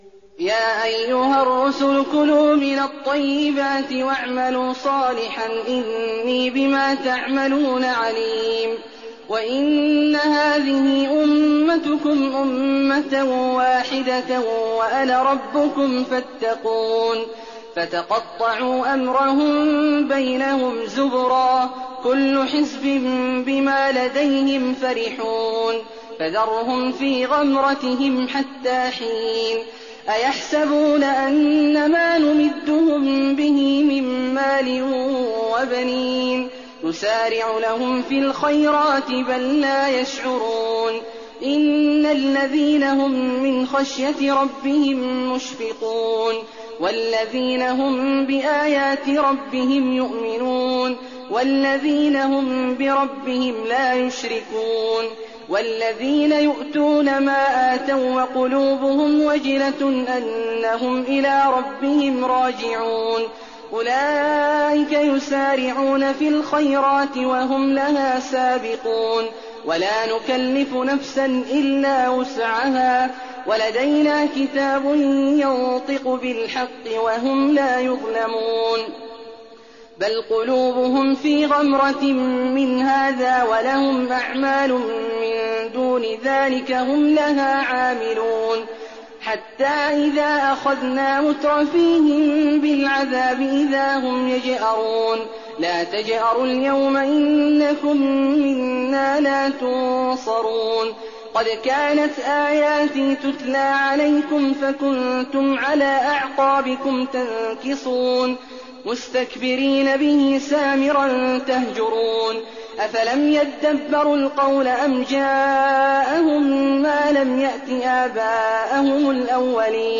تراويح الليلة السابعة عشر رمضان 1419هـ من سورتي المؤمنون (51-118) و النور (1-45) Taraweeh 17th night Ramadan 1419H from Surah Al-Muminoon and An-Noor > تراويح الحرم النبوي عام 1419 🕌 > التراويح - تلاوات الحرمين